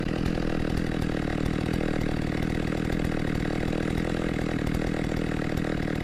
Звуки бензопилы
Шум холостого хода бензопилы